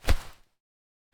Cloth, Handle, Grab Person Wearing Raincoat SND16659 S00.wav